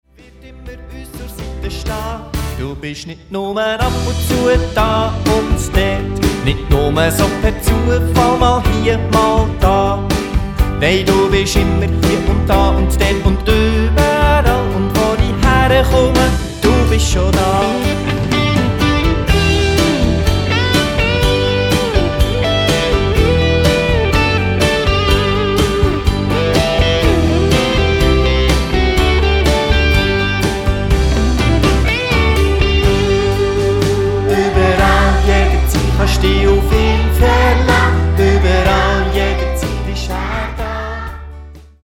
Und natürlich machen wieder Kinder mit!